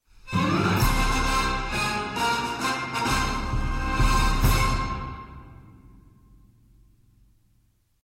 Meme Sound Effect for Soundboard